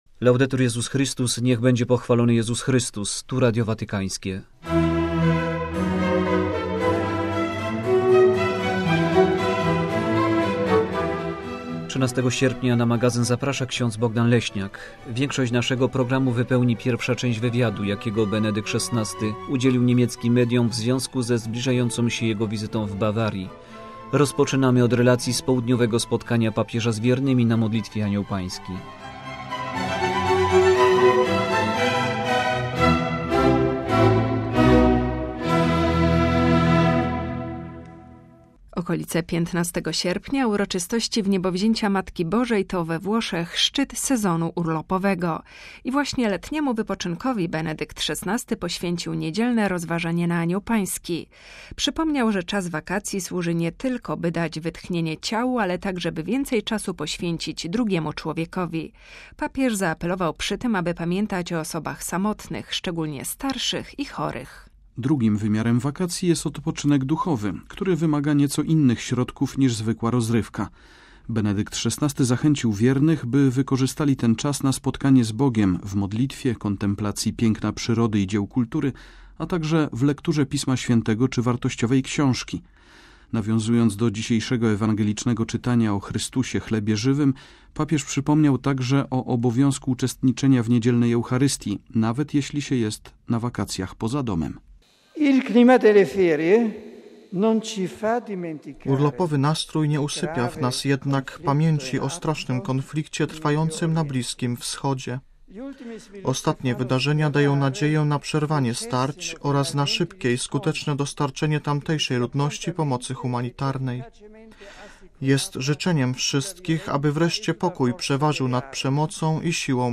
5 sierpnia w Castel Gandolfo Benedykt XVI udzielił niemieckim mediom wywiadu w związku z przygotowaniami do wizyty w Bawarii. Rozmowę przeprowadzili dziennikarze rozgłośni: ARD, ZDF, Deutsche Welle i Radia Watykańskiego. Dziś prezentujemy pierwszą część wywiadu. Oprócz tego relacja z południowego spotkania Benedykta XVI z wiernymi w Castelgandolfo.